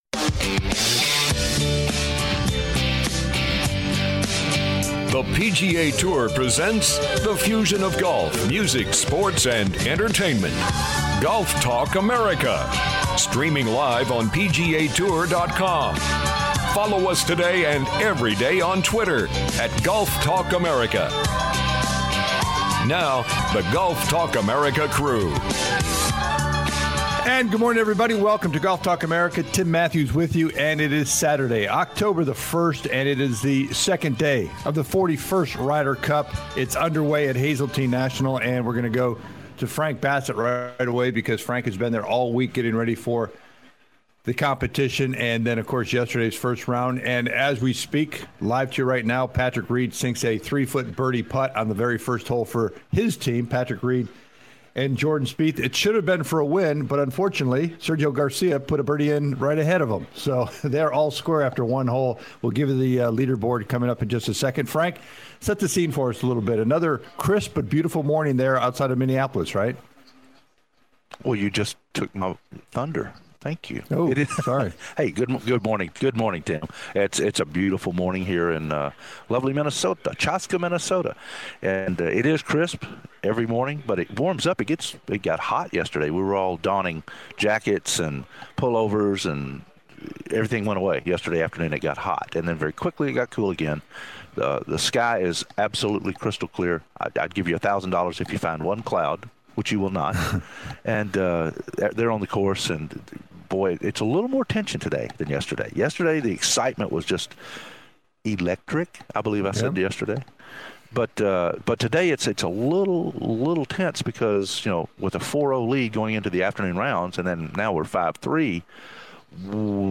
"LIVE" Day 2 from The Ryder Cup